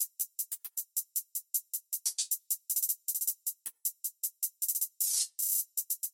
描述：156 BPM。Trap shxt.TTTTTTTx3
Tag: 156 bpm Trap Loops Drum Loops 1.04 MB wav Key : Unknown